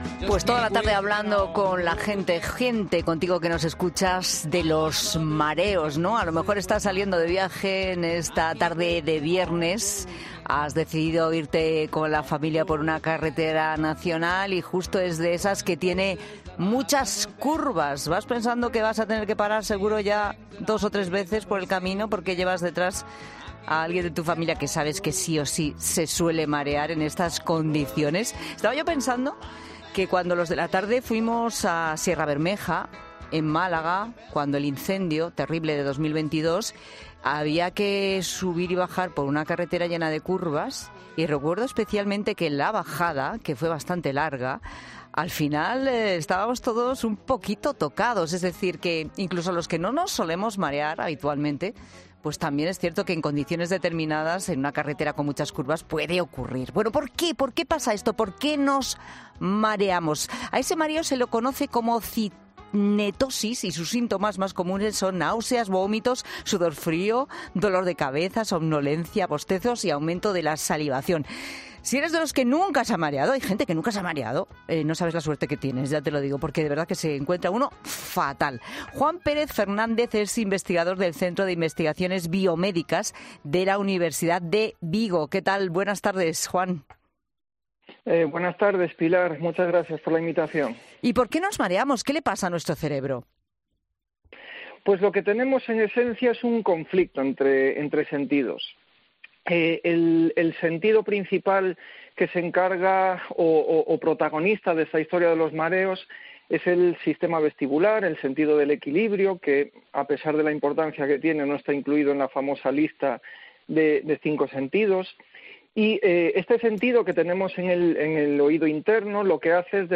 En el siguiente audio, el investigador explica en profundidad por qué se produce la cinetosis durante los trayectos en coche y qué papel desempeña cada uno de estos sentidos.